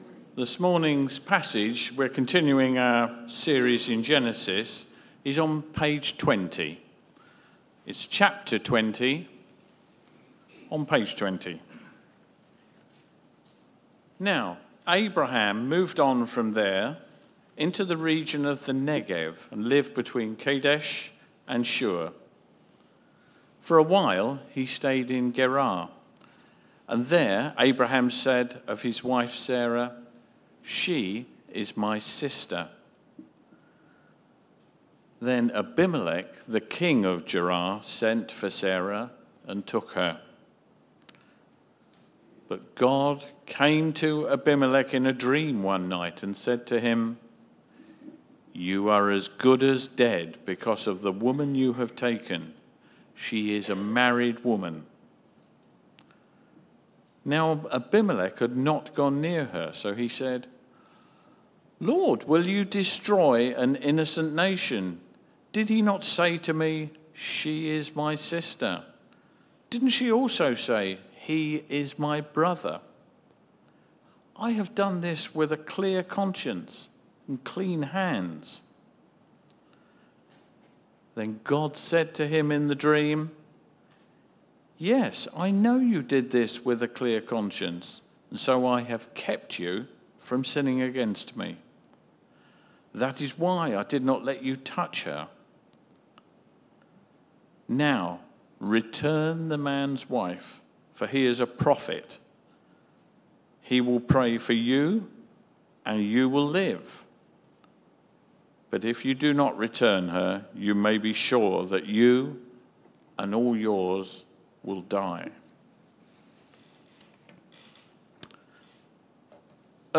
Media for Sunday Service on Sun 17th Mar 2024 10:00
Passage: Genesis 20, Series: Genesis Theme: Sermon